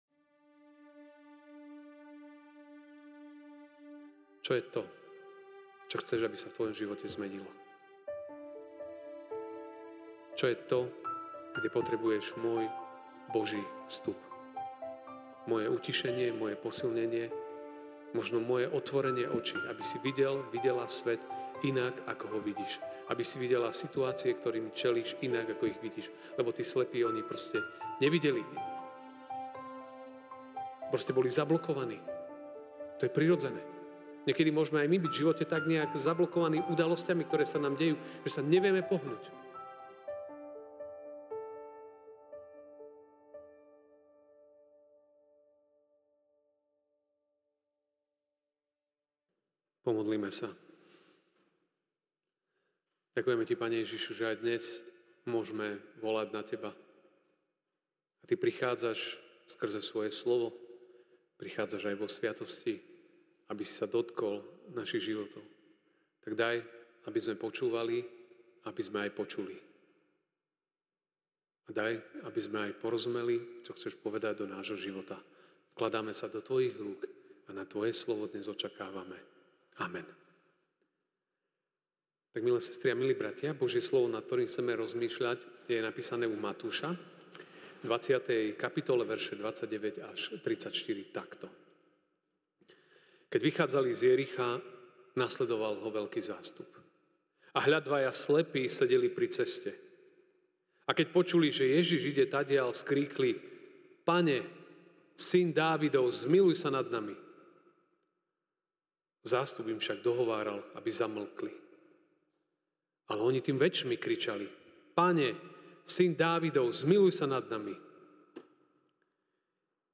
aug 18, 2024 Čo chceš, aby sa stalo v tvojom živote MP3 SUBSCRIBE on iTunes(Podcast) Notes Sermons in this Series Večerná kázeň: Mt(20, 29-34) „ Keď vychádzali z Jericha, nasledoval Ho veľký zástup.